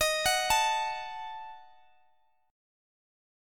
Ebdim Chord
Listen to Ebdim strummed